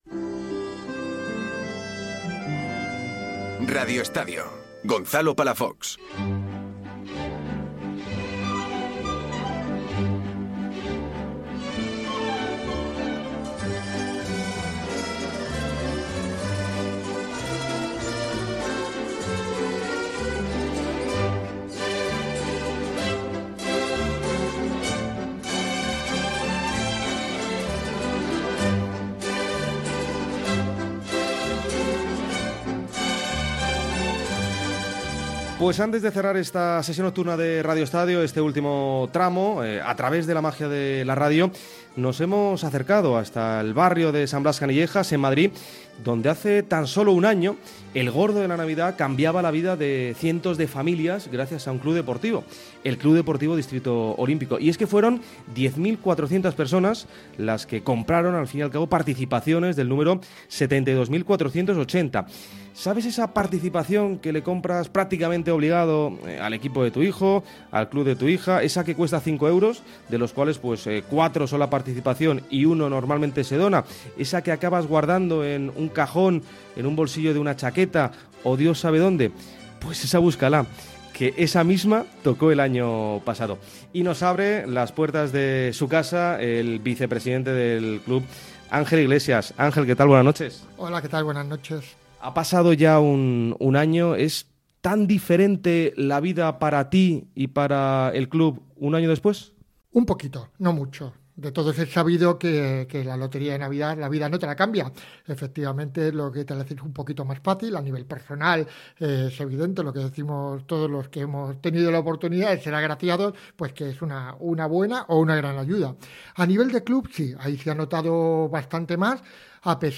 Todo el deporte en directo. El eje que sujeta al programa será el fútbol y LaLiga, pero no perdemos detalle de todos los deportes del país, y de aquellos eventos en el que participen nuestros deportistas por el mundo.